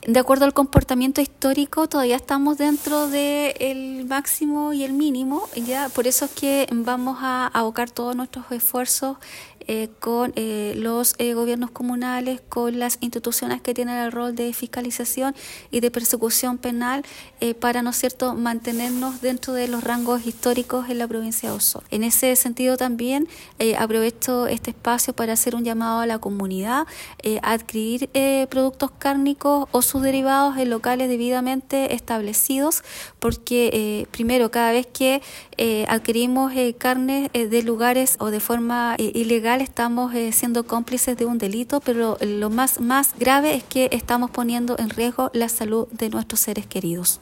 La Delegada Presidencial Claudia Pailalef explicó que el objetivo de esta instancia fue poder abordar este tipo de delitos que se dan con mayor frecuencia en la comuna de Puyehue, lo que podría aumentar de cara a las fiestas de fin de año.